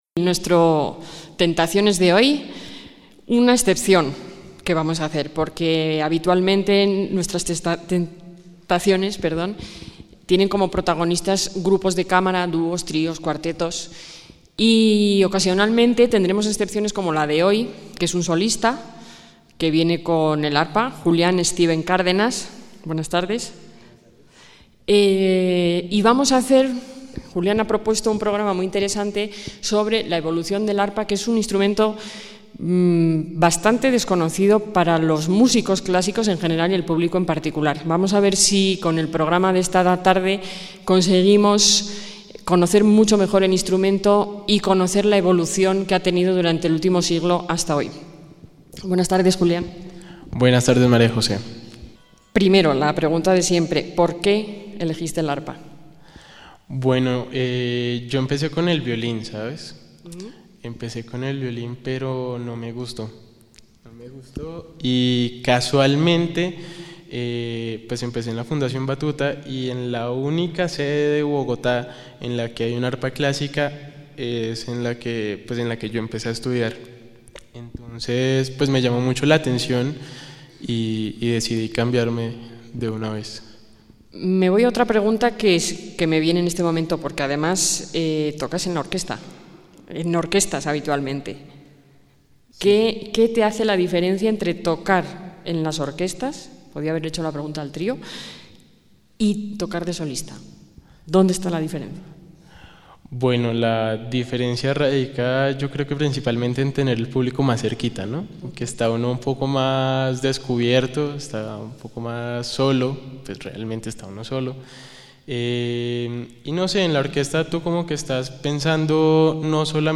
JÓVENES TALENTOS EN EL PROGRAMA TENTACIONES GRABADO EN EL AUDITORIO FABIO LOZANO
arpista